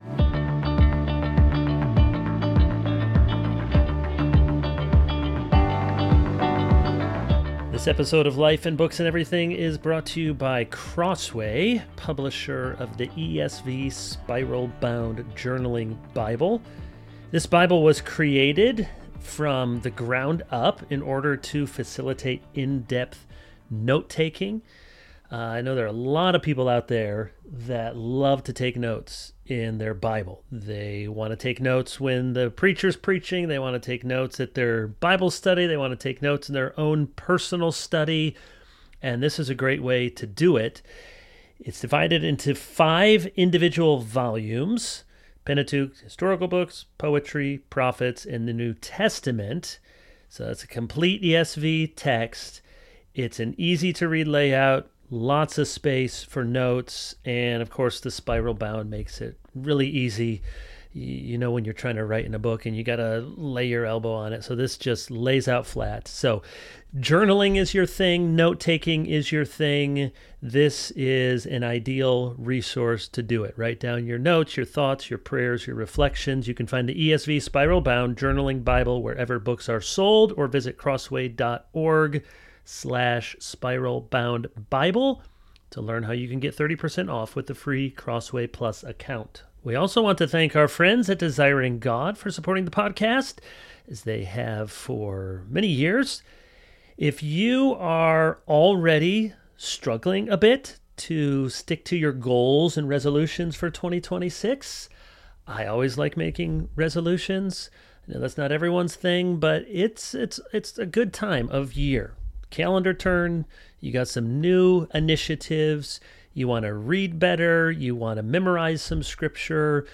Episode Description In this timely interview